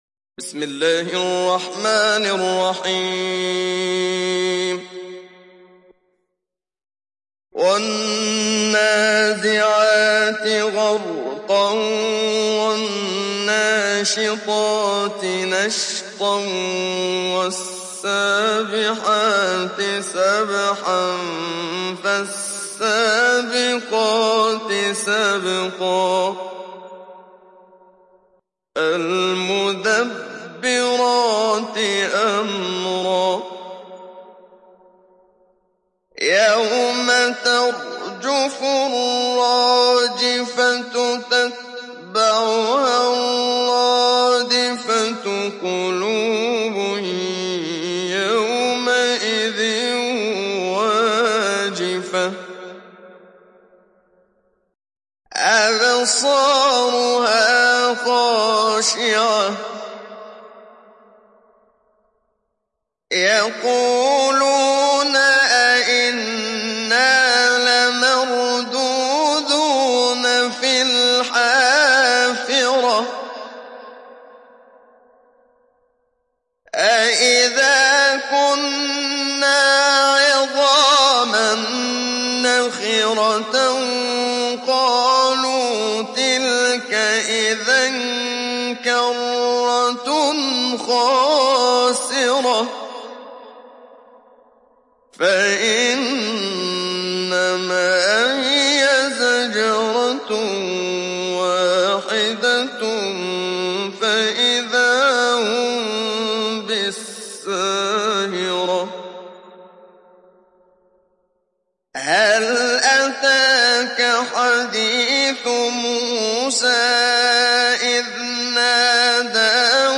Télécharger Sourate An Naziat Muhammad Siddiq Minshawi Mujawwad